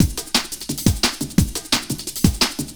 Index of /neuro/Stanza/Drums/Drum Loops